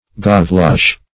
Gaveloche \Gav"e*loche\, n.